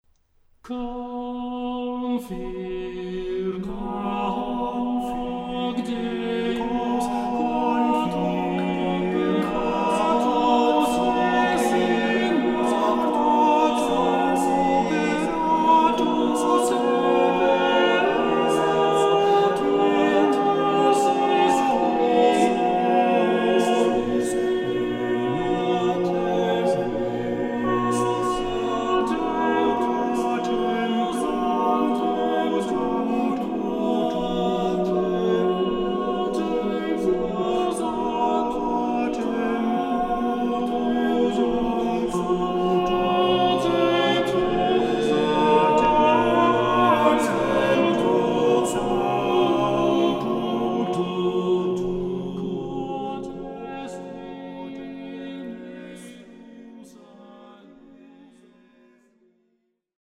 Shop / CDs / Vokal